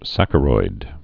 (săkə-roid) or sac·cha·roi·dal (-roidl)